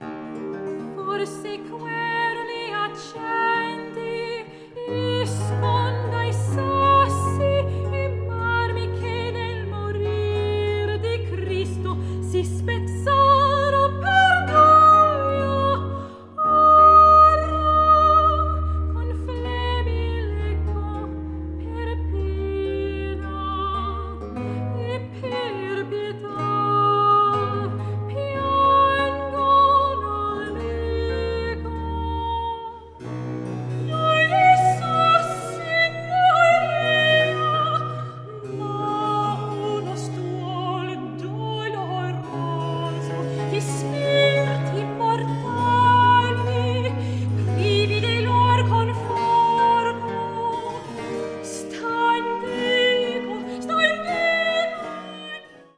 Early-music ensembles TENET Vocal Artists and ACRONYM have joined forces to make the first commercial recording of a sumptuous work by composer Johann Heinrich Schmelzer (1623-1680), a sepolcro set in the tomb of Christ.
TENET Vocal Artists
ACRONYM